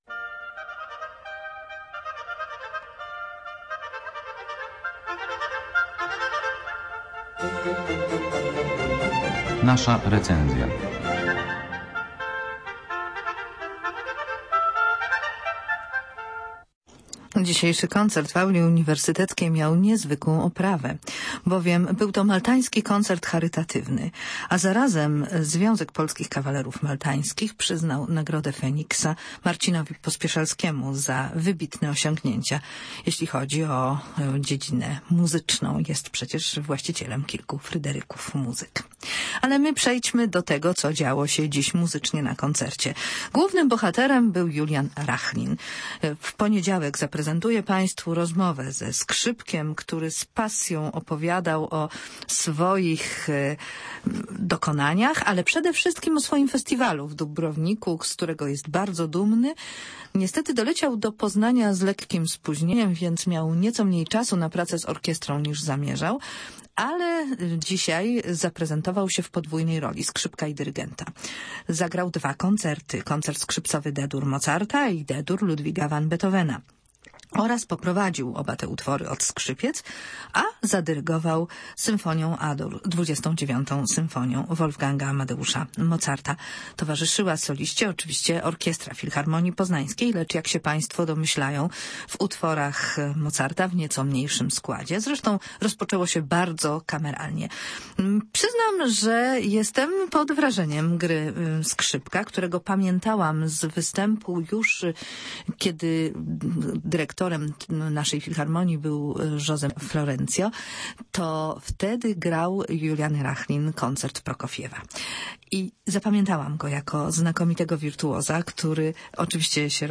ccqmmk9xfe7y951_rachlin_recenzja.mp3